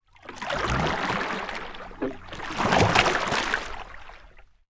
Water_46.wav